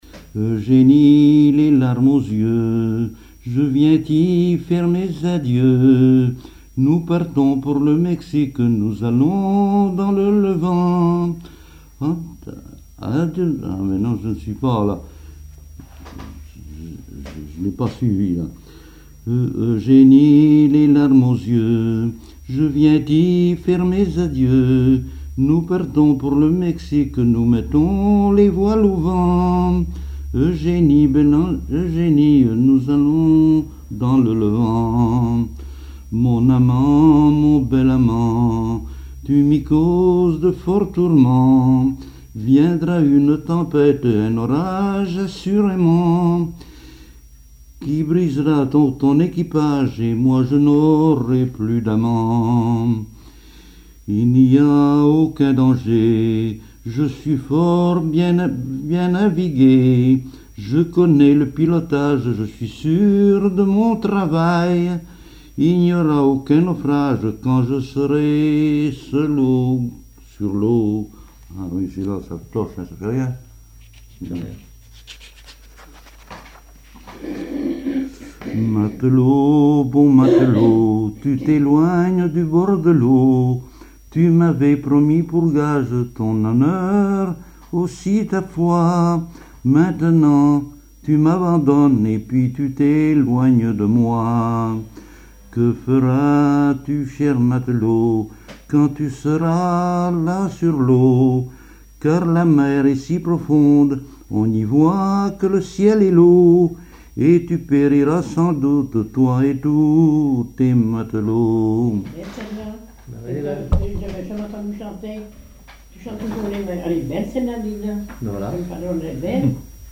chanteur(s), chant, chanson, chansonnette
Pièce musicale inédite